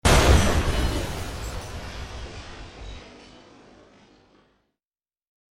explosion_glass.mp3